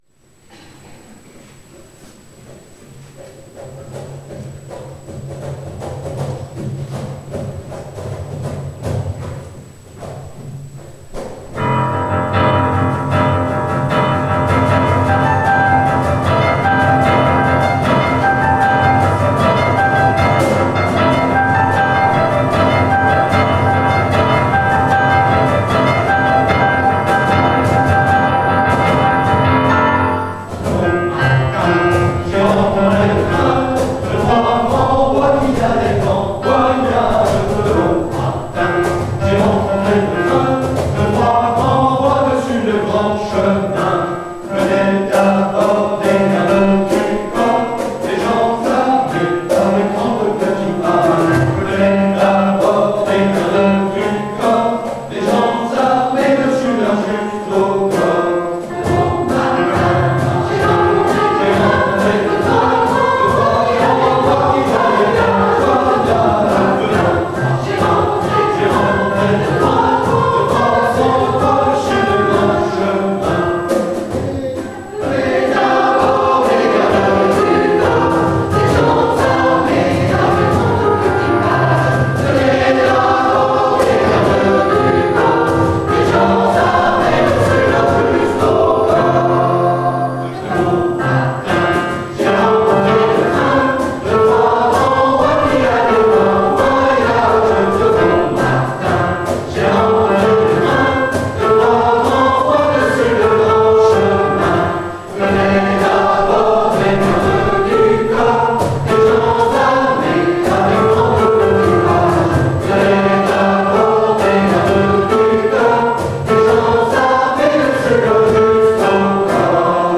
Pour ceux qui vous sont proposés à l'écoute, vous voudrez bien excuser les parasites et la qualité inégale des enregistrements, tous réalisés en public.